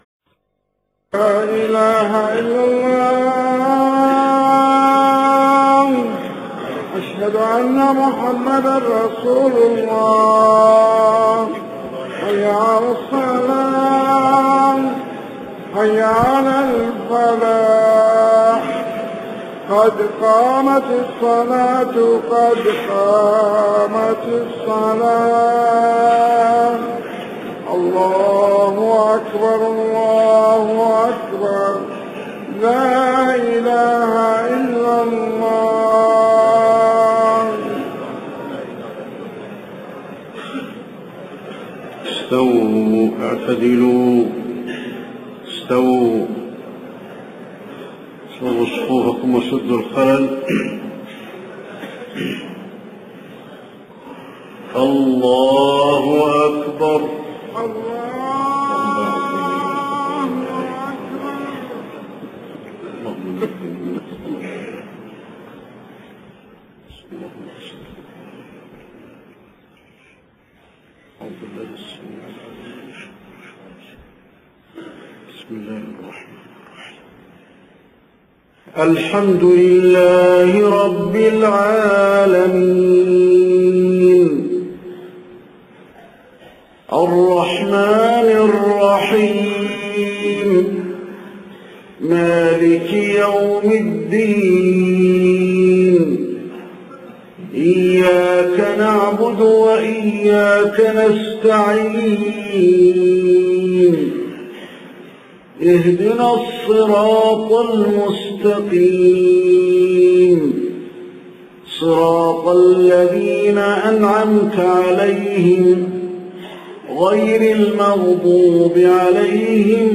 صلاة العشاء 6 محرم 1430هـ من سورة آل عمران 102-110 > 1430 🕌 > الفروض - تلاوات الحرمين